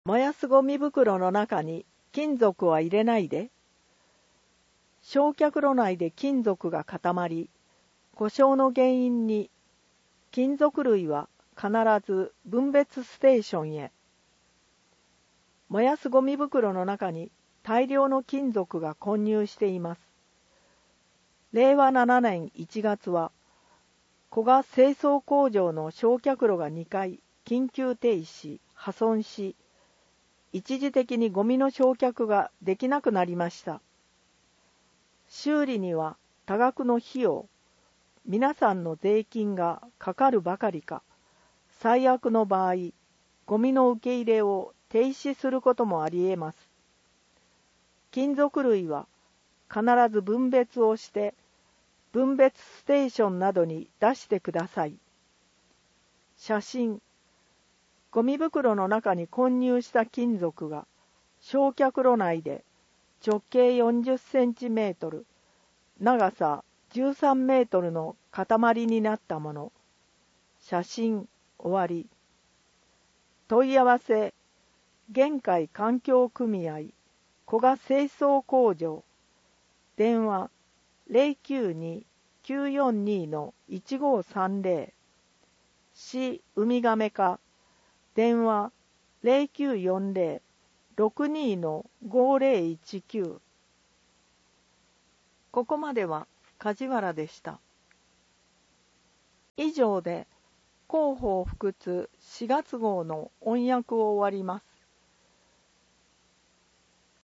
広報ふくつを音声で聞けます
音訳ボランティアふくつの皆さんが、毎号、広報ふくつを音訳してくれています。